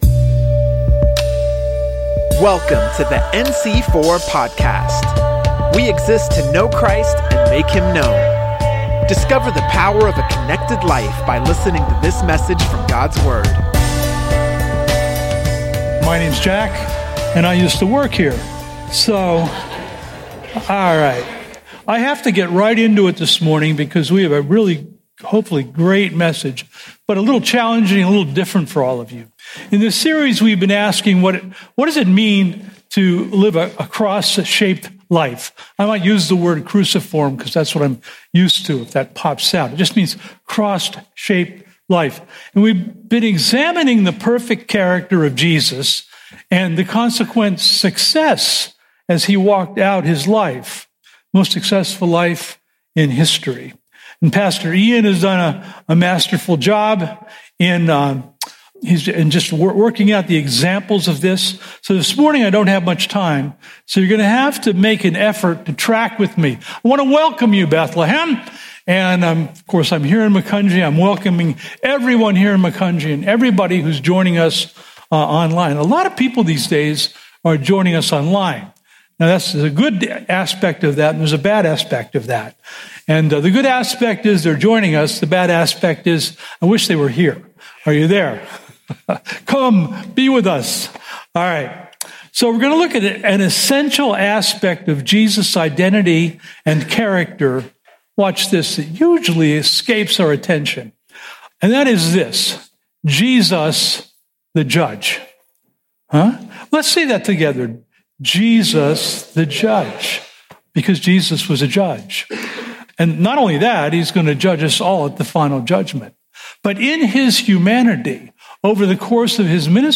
NC4 Sermons Podcast - Pilate Before Jesus: Here Comes the Judge!